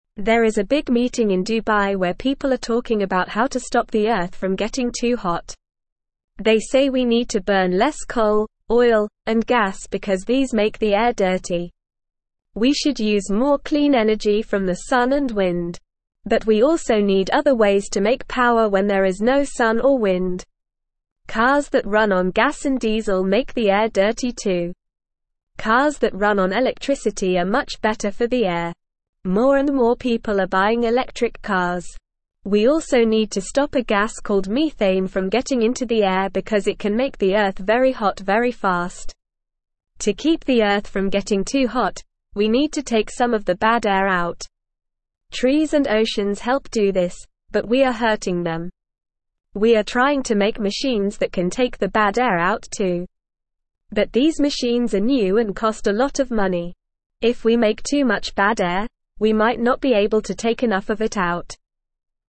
Normal
English-Newsroom-Lower-Intermediate-NORMAL-Reading-Ways-to-Help-Our-Planet-and-Clean-the-Air.mp3